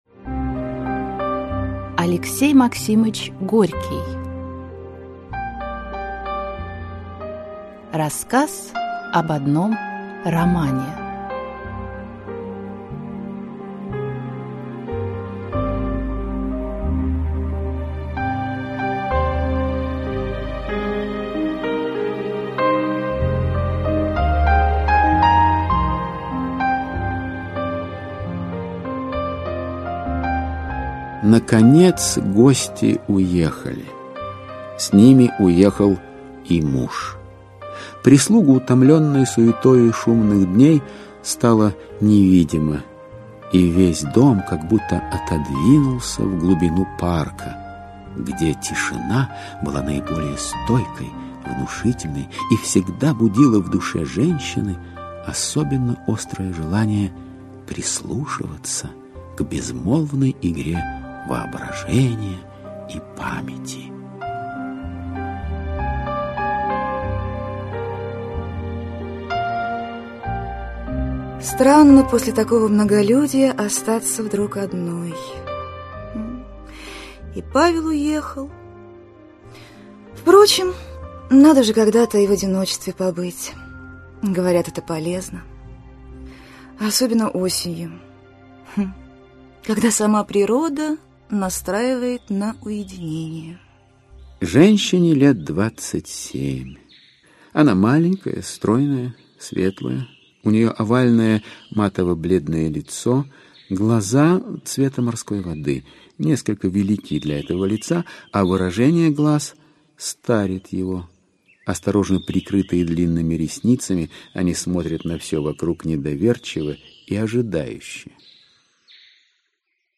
Аудиокнига Рассказ об одном романе. Аудиоспектакль | Библиотека аудиокниг